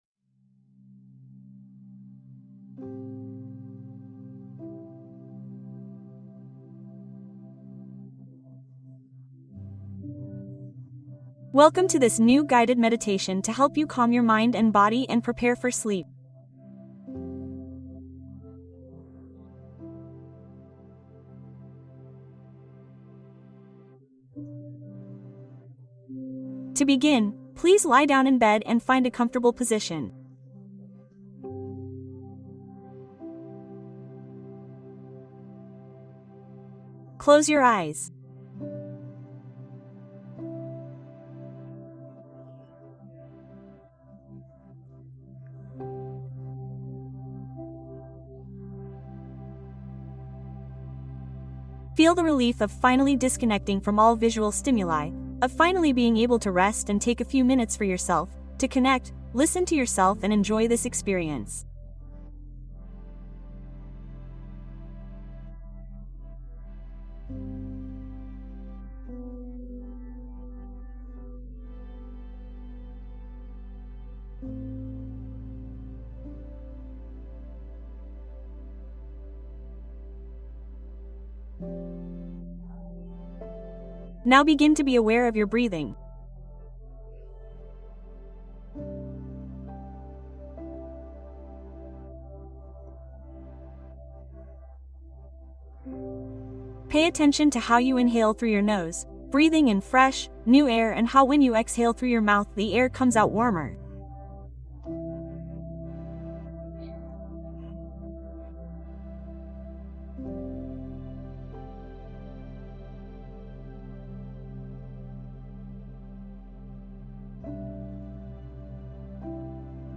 Meditación + Cuento Para dormir profundamente con historias suaves